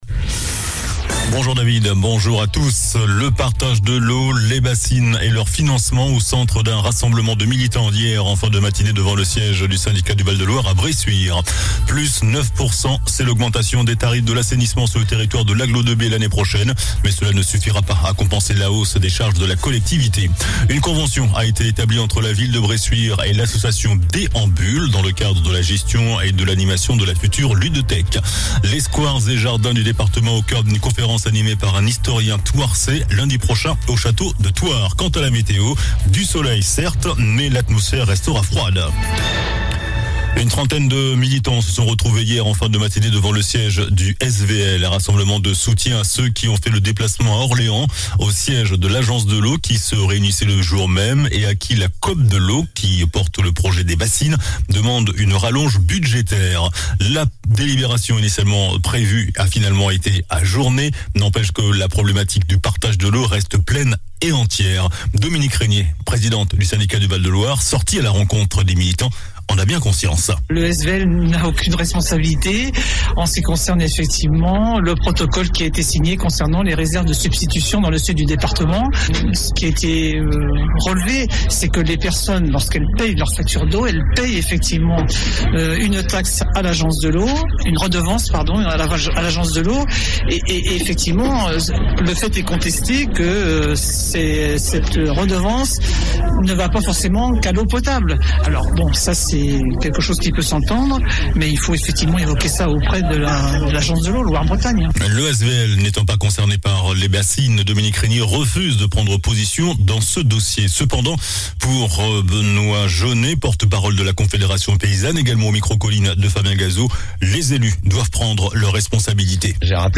JOURNAL DU VENDREDI 16 DECEMBRE ( MIDI )